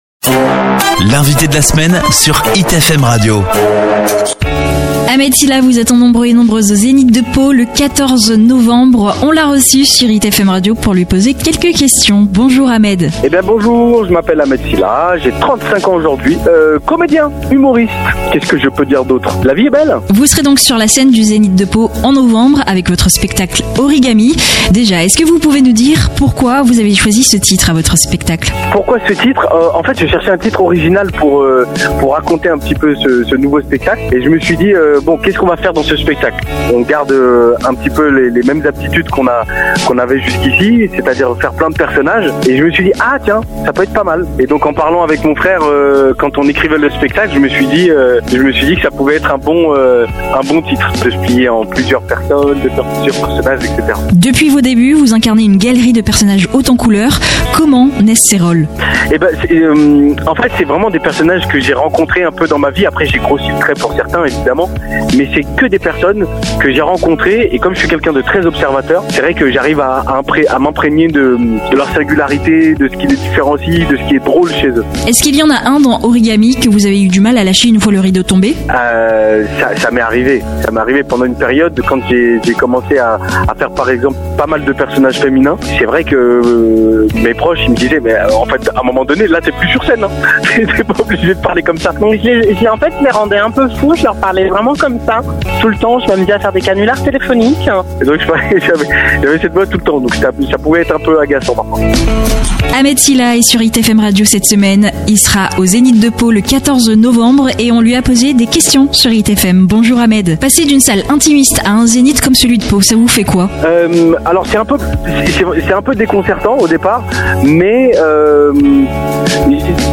Cette semaine, nous avons eu le plaisir de recevoir Ahmed Sylla, l’invité de la semaine d'Hit FM Radio.
Une rencontre pleine de générosité, de rires et de profondeur, à l’image d’un artiste qui ne cesse de se réinventer et de surprendre.